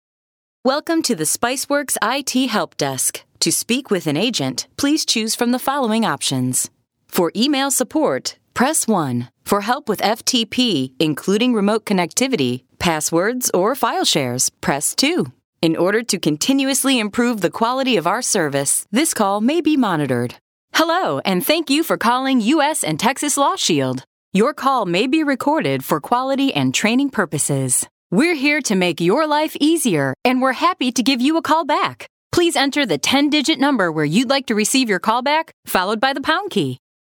IVR
Micrófono MKH 415, Pro Tools, estudio insonorizado con funciones de masterización.